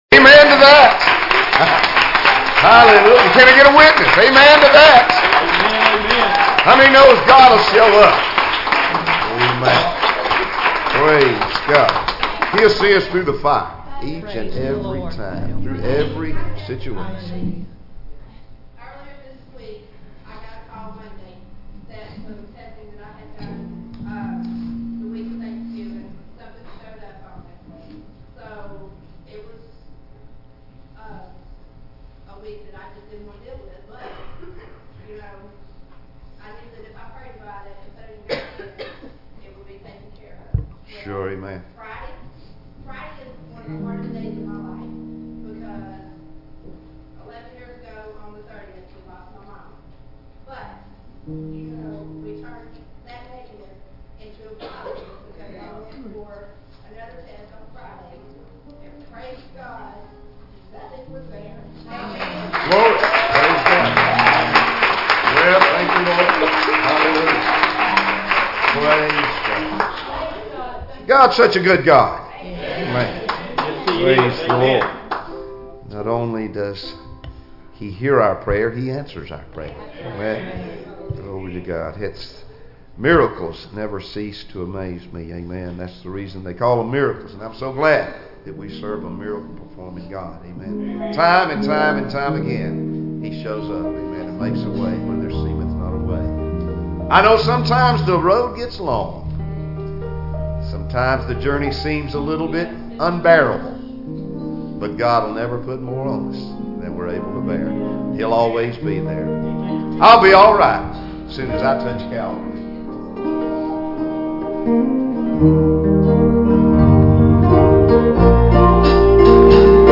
Passage: Exodus 1:6-12 Service Type: Sunday Morning Services Topics